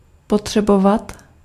Ääntäminen
Synonyymit rekenen vorderen eisen voorschrijven vergen opeisen Ääntäminen Tuntematon aksentti: IPA: /vɛrˈɛɪsə(n)/ Haettu sana löytyi näillä lähdekielillä: hollanti Käännös Ääninäyte 1. potřebovat Luokat Verbit